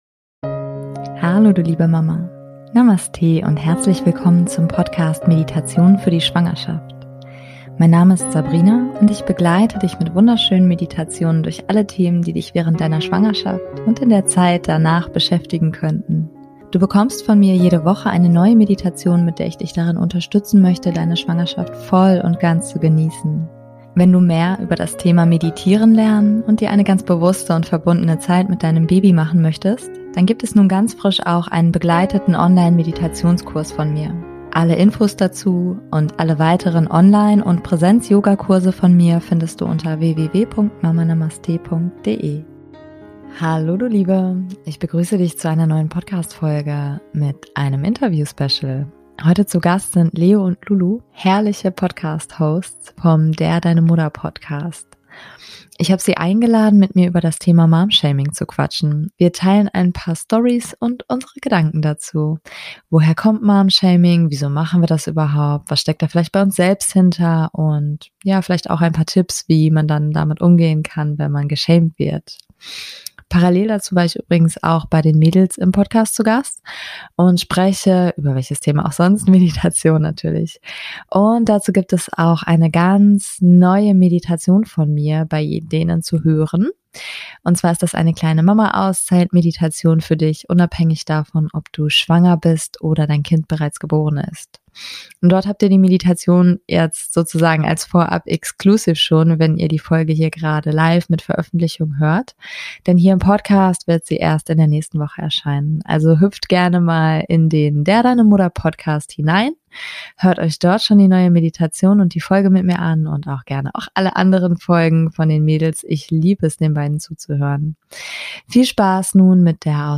Beschreibung vor 3 Jahren Hallo ihr Lieben, ich begrüße euch zu einer neuen Podcastfolge mit einem Interview Special.